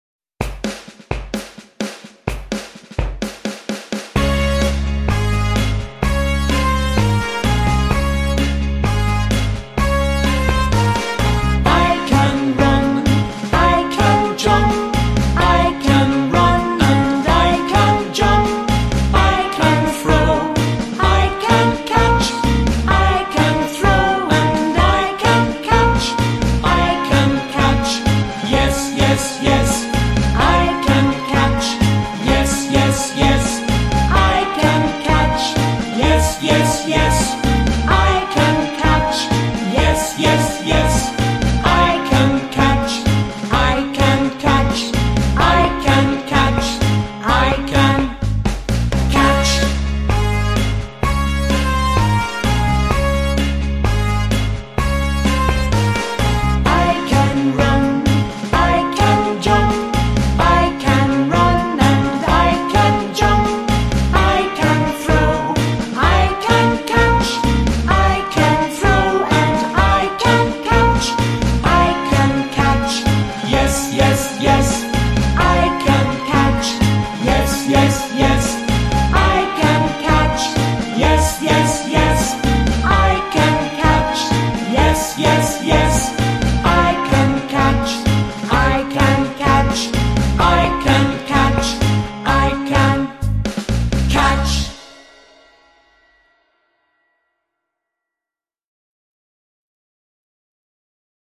Песенка для изучения английского языка для маленьких детей.